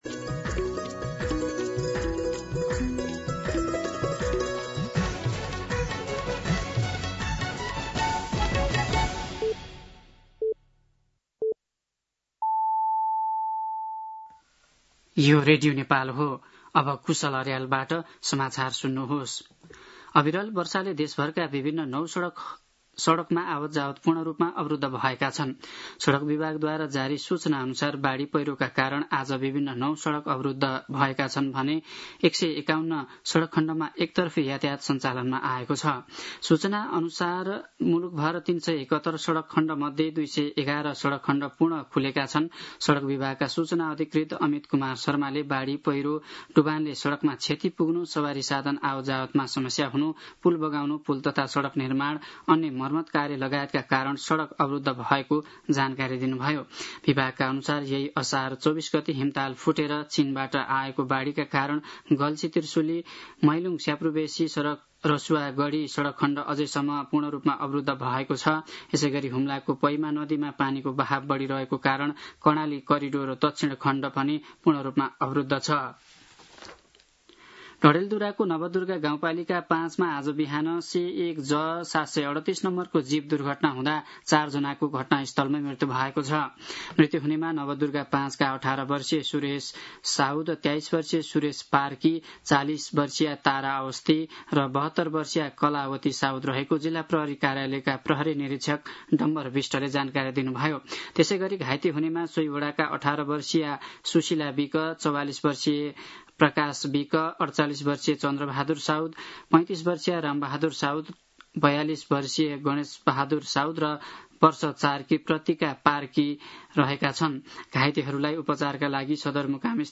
दिउँसो ४ बजेको नेपाली समाचार : १२ भदौ , २०८२
4pm-News-05-12.mp3